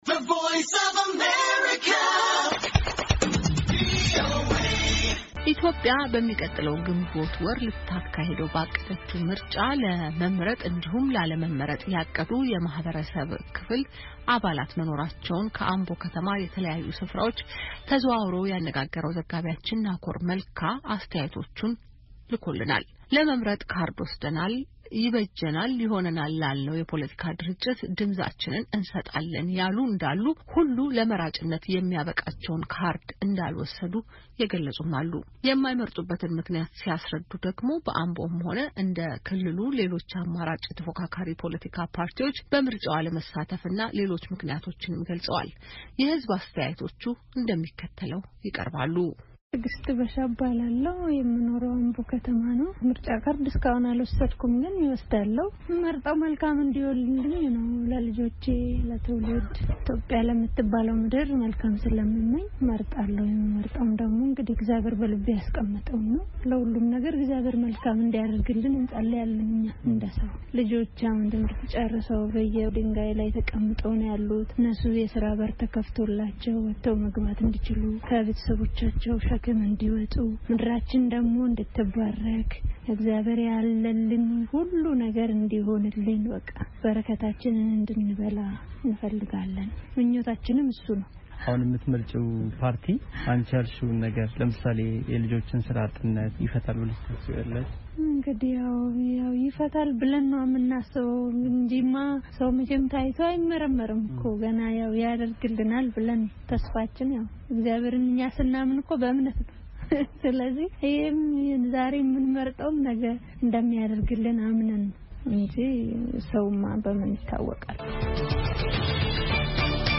ኢትዮጵያ በሚቀጥለው ግንቦት ወር ልታካሂደው ባቀደችው ምርጫ ለመምረጥ እንዲሁም ላለመምረጥ ያቀዱ የማኅበረሰብ ክፍል አባላት መኖራቸውን ከአምቦ ከተማ የተለያዩ ስፍራዎች ተዘዋውሮ ያነጋገረው ዘጋቢያችን አስተያየቶቹን ልኮልናል።